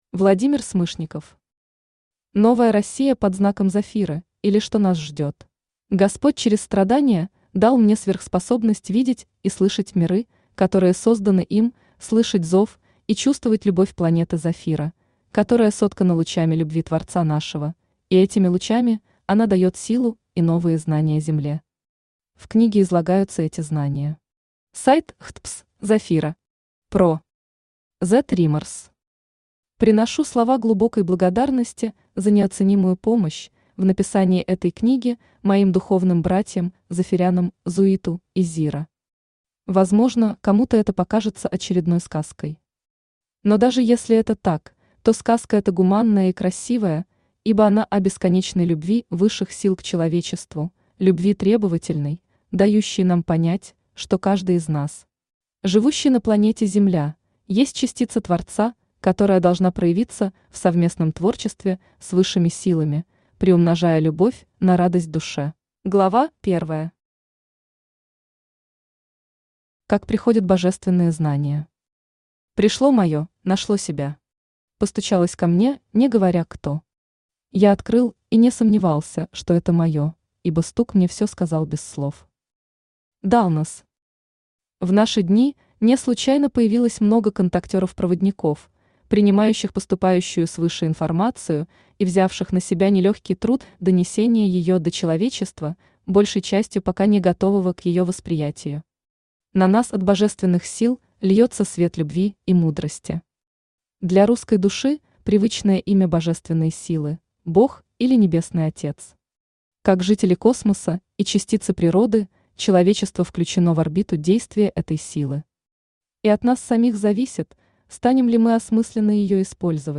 Аудиокнига Новая Россия под знаком Зафиры, или Что нас ждет…
Автор Владимир Николаевич Смышников Читает аудиокнигу Авточтец ЛитРес.